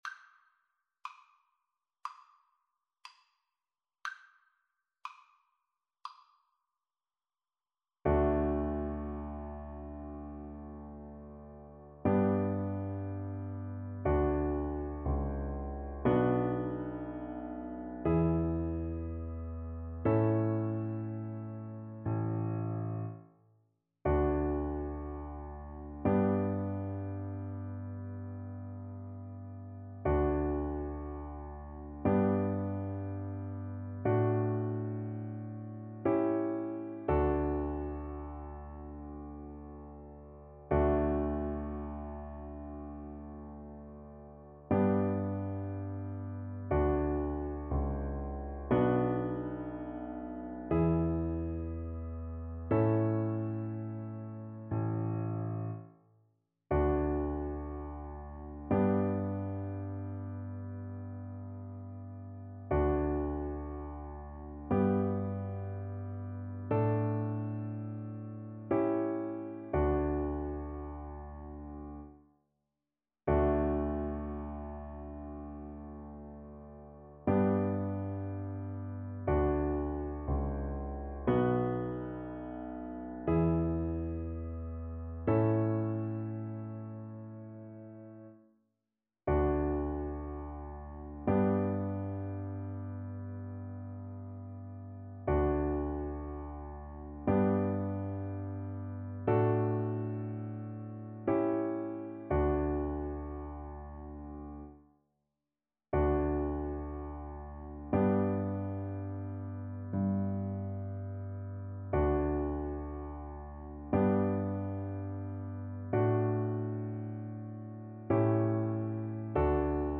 ~ = 100 Adagio
4/4 (View more 4/4 Music)
Classical (View more Classical Viola Music)